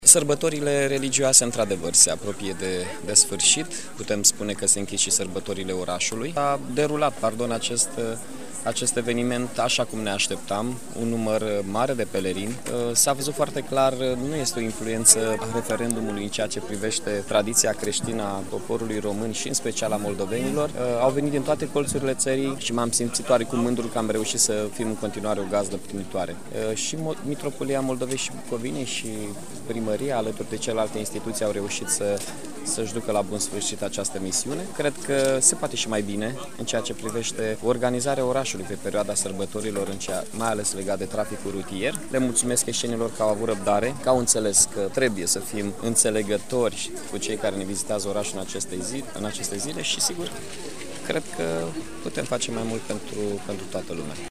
Primarul municipiului Iasi a trecut ieri in revista la masa pelerinilor punctele tari si punctele slabe ale organizarii din acest an